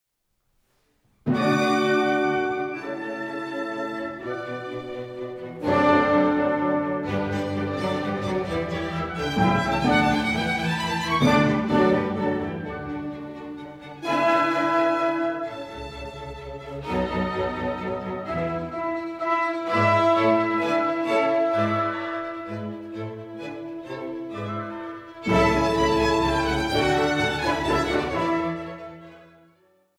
第17回 モーツァルト交響曲・全曲演奏会
交響曲 第８番 ニ長調 KV48